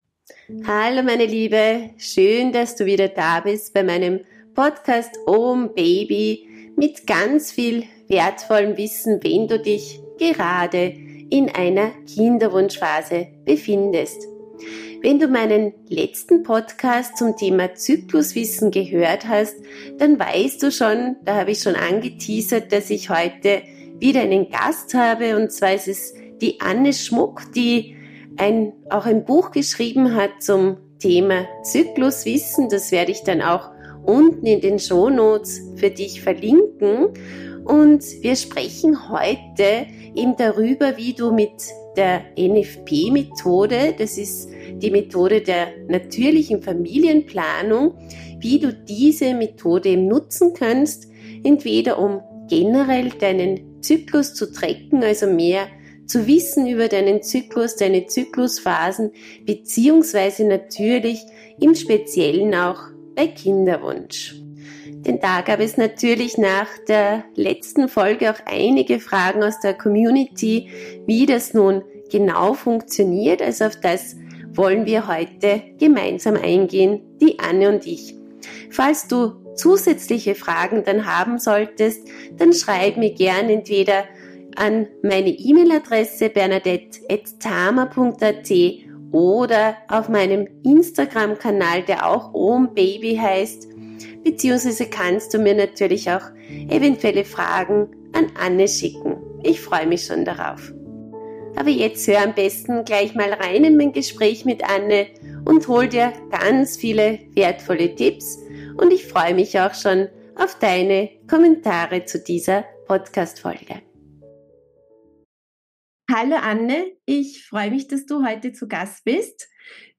Expertinneninterview